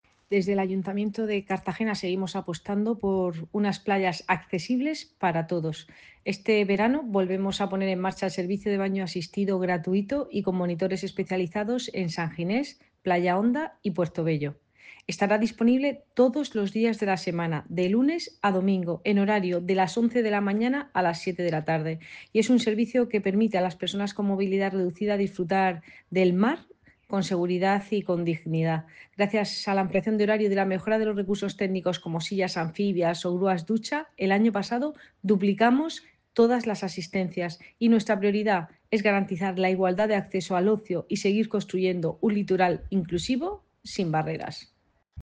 Enlace a Declaraciones de Cristina Mora sobre servicio de baño asistido en playas